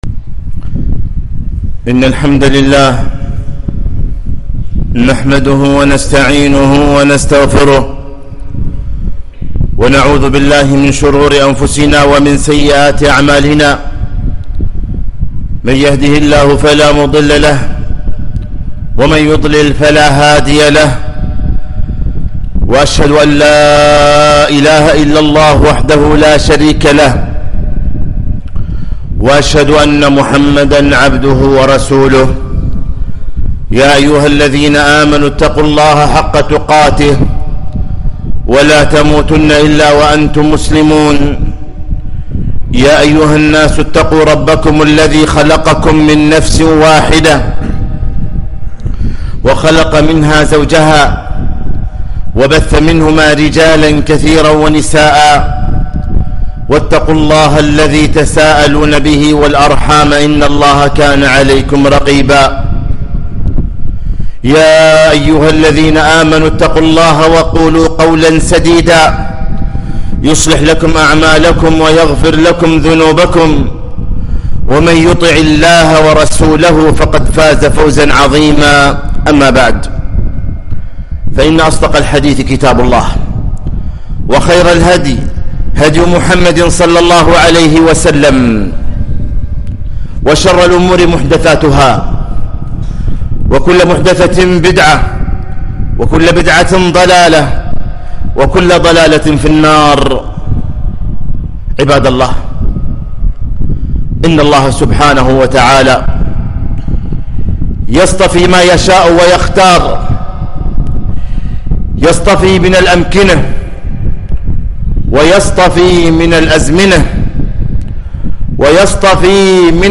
خطبة - أفضل أيام الدنيا